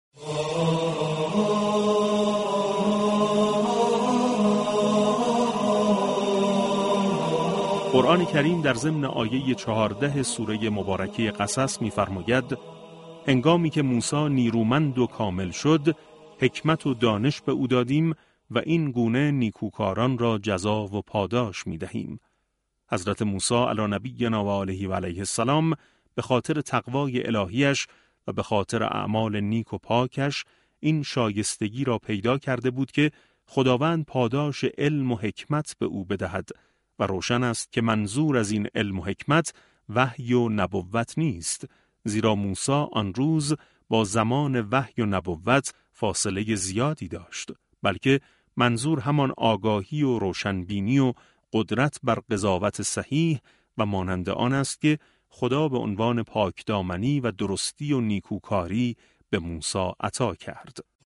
به گزارش پایگاه اطلاع رسانی رادیو قرآن ، در رادیو قرآن، به شرح آیه چهاردهم از سوره مباركه «قصص» پرداخته شد و كارشناس برنامه شرح و توضیح آیات با اشاره به دوران رشد و بلوغ حضرت موسی(ع) گفت: قرآن كریم در این آیه بیان می‌دارد كه هنگامی كه موسی نیرومند و كامل شد، خداوند به او علم و حكمت عطا كرد و این پاداشی است كه پروردگار برای نیكوكاران مقرر می‌فرماید.